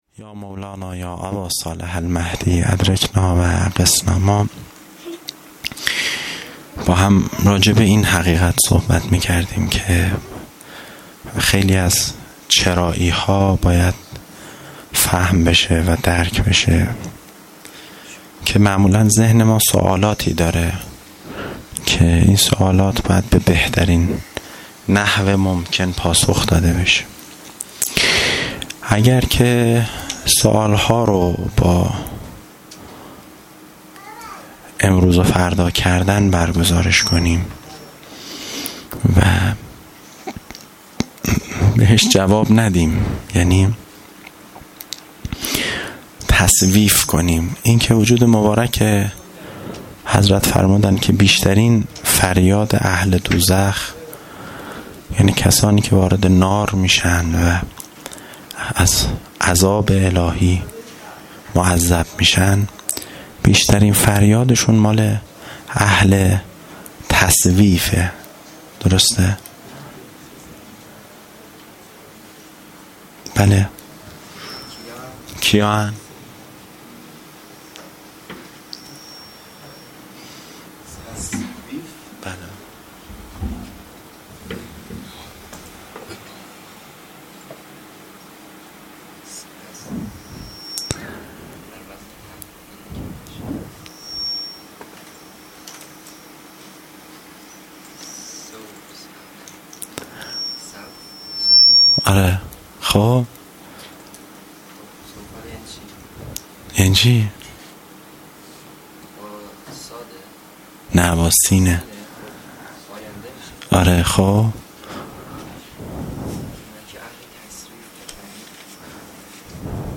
پنجشنبه ۲۹ تیرماه ۱۴۰۲ - هیئت جوانان ریحانه الحیدر سلام الله علیها